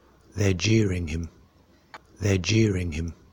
The pronunciation of /ʤ/ and /ʧ/
U1_T3_cheering_jeering1.mp3